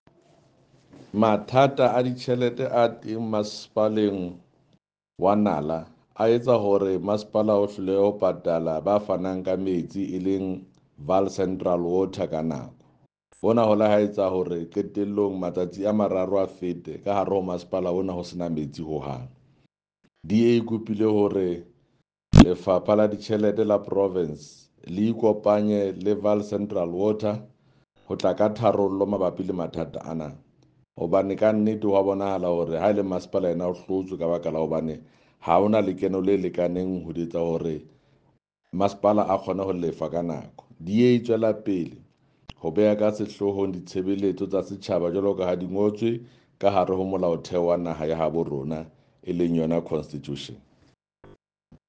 Sesotho by Jafta Mokoena MPL.
Sotho-voice-Jafta-7.mp3